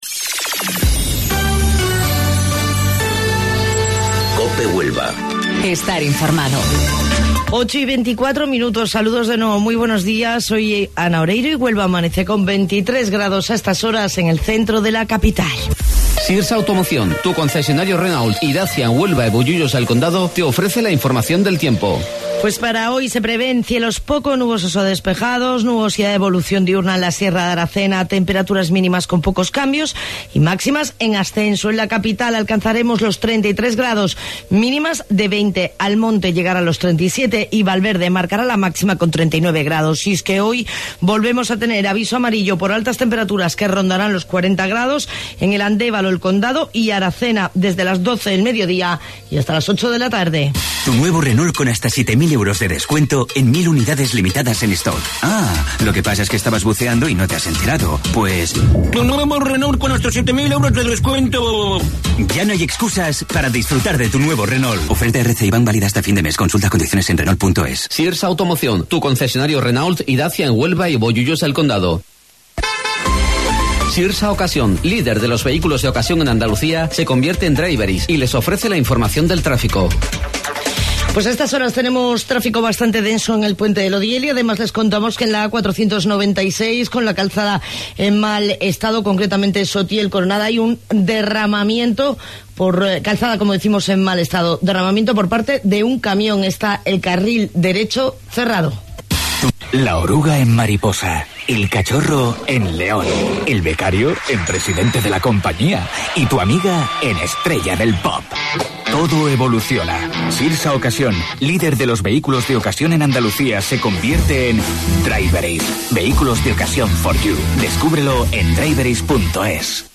AUDIO: Informativo Local 08:25 del 22 de Julio